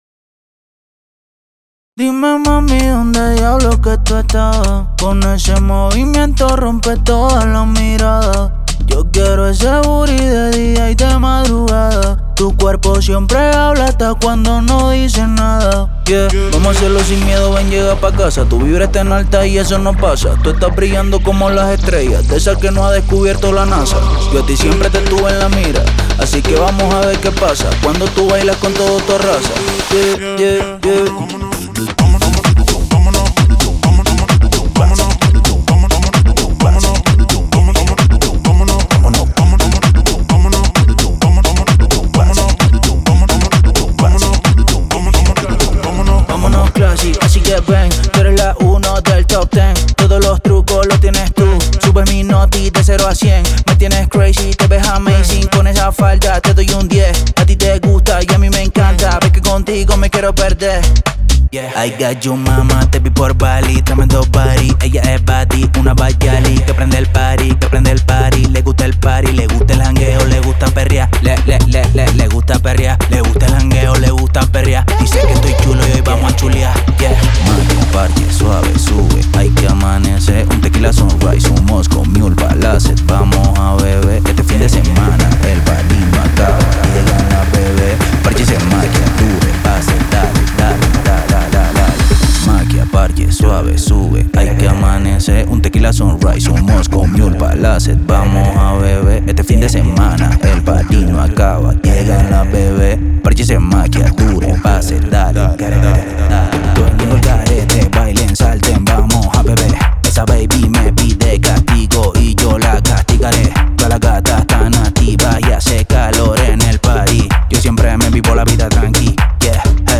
テックハウス系では特に使い勝手も良く、 重宝するサンプルパックです。
Genre:Tech House
注意事項として、オーディオデモは音量が大きく、コンプレッションされ、均一に聞こえるよう処理されています。
127 – 130 BPM